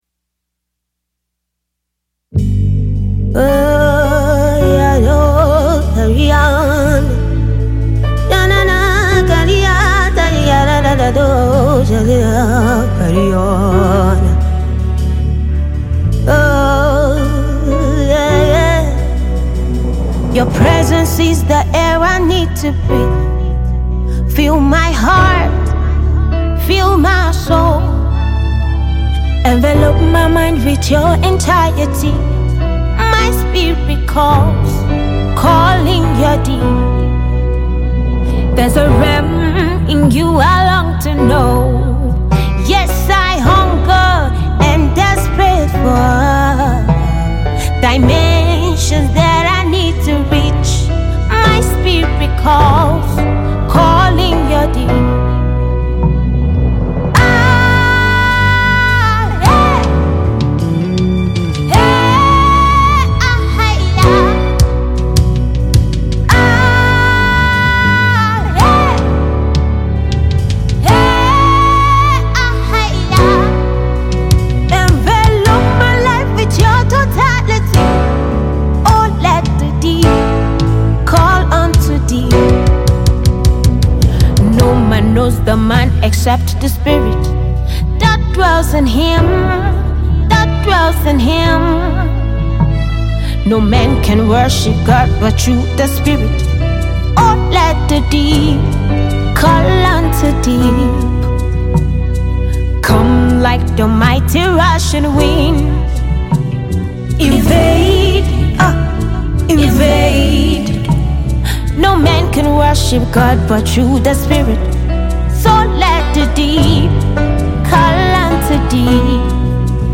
Fast rising female gospel music minister
worship song